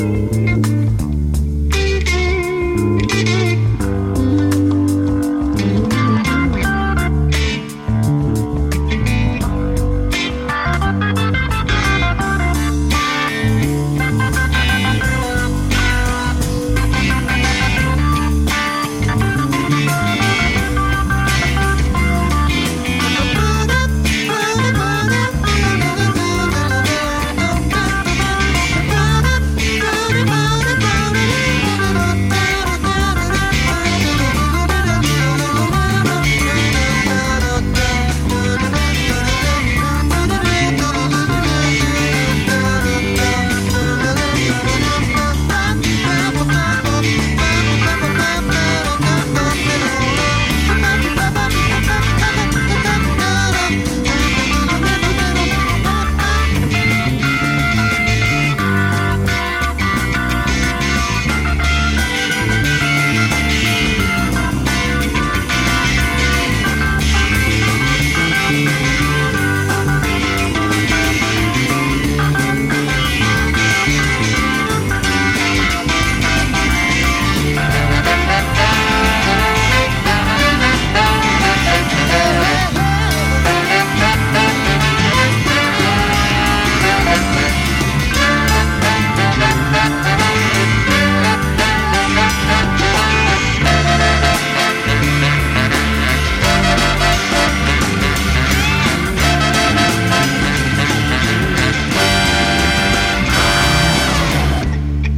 batterie